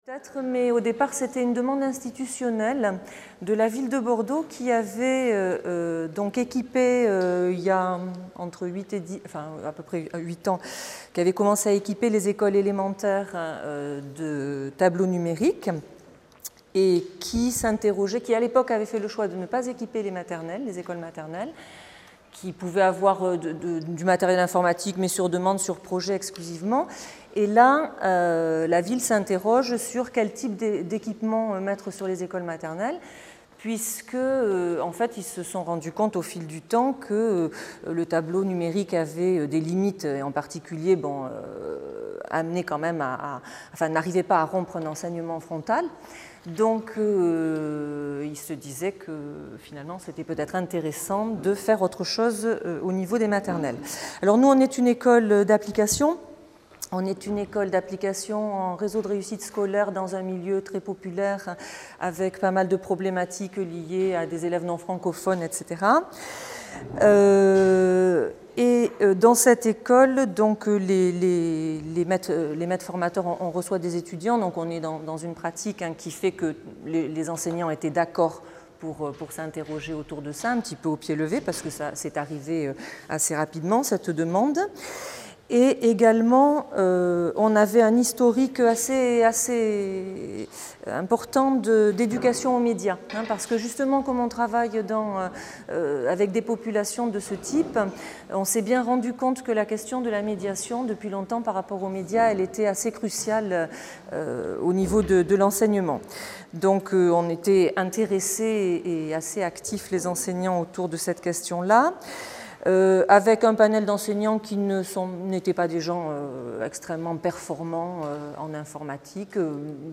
Journée d’étude organisée par l’EHESS en collaboration avec le ministère de l’éducation nationale 8 avril 2014, Salle des Conférences, Lycée Henri IV, 75005 Paris Maintenant, les objets communicants font partie de l’environnement des enfants dès leur naissance.